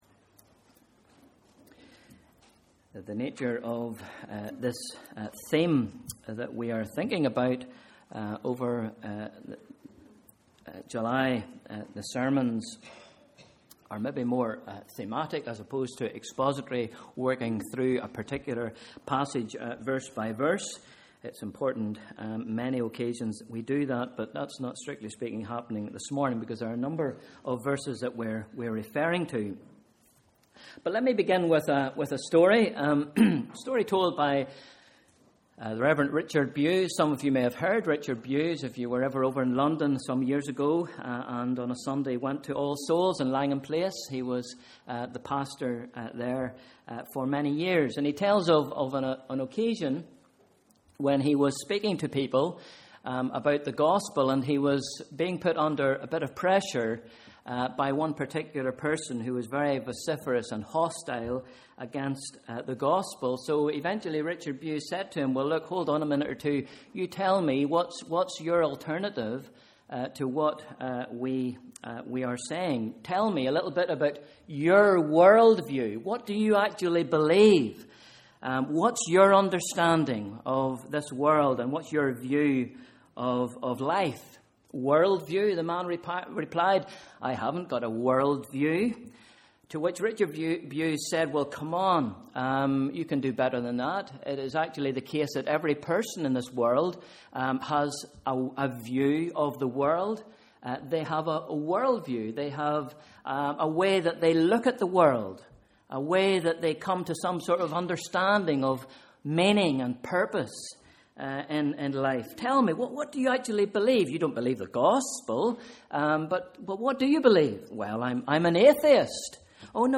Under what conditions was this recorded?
Morning Service: Sunday 7th July 2013